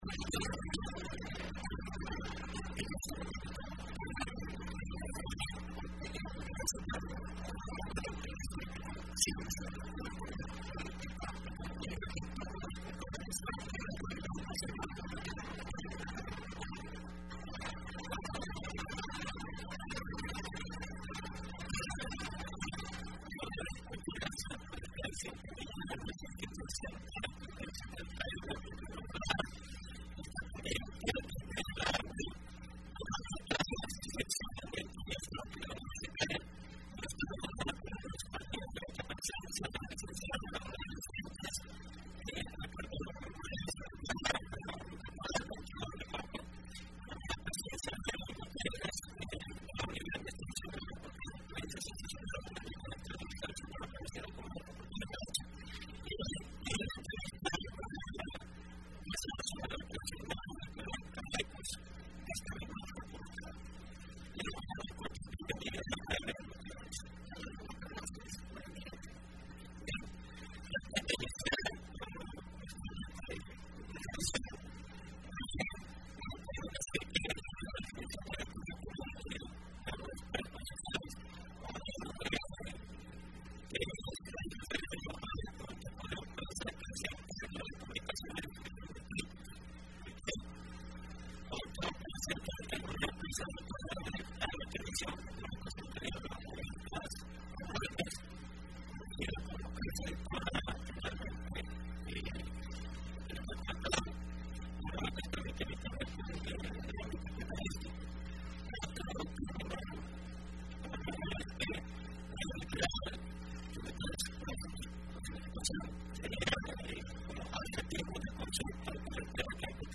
Entrevista Opinión Universitaria (28 de abril de 2015): Creación de la Editorial Aequus, de la Facultad de Jurisprudencia y Ciencias Sociales de la UES.